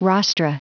Prononciation du mot rostra en anglais (fichier audio)
Prononciation du mot : rostra